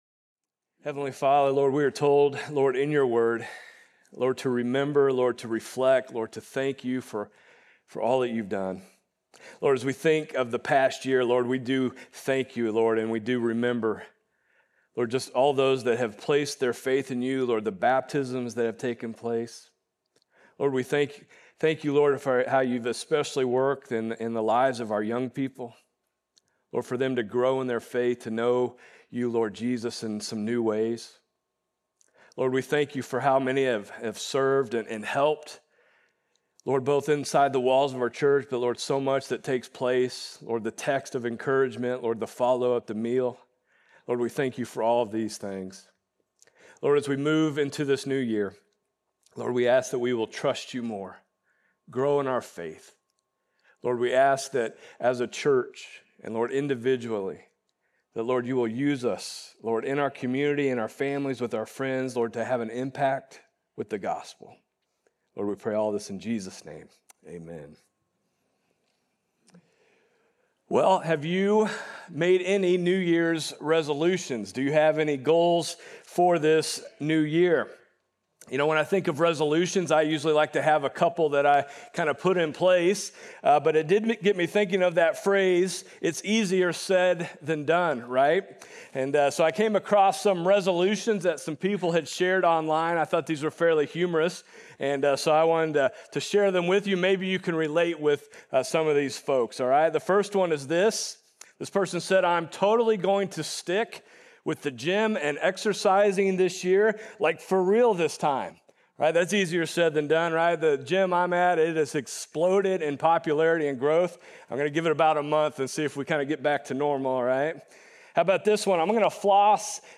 Sermons | FBC Platte City